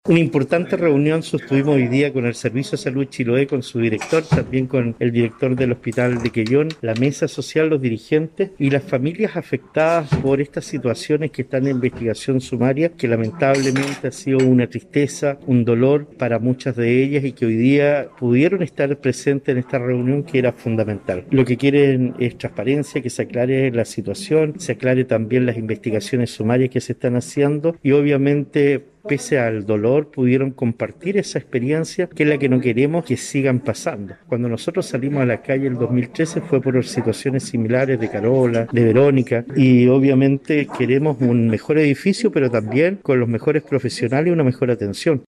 En este sentido el alcalde Cristian Ojeda, calificó la reunión con el Director del Servicio de Salud Chiloé y la Mesa Social de Salud, como muy importante.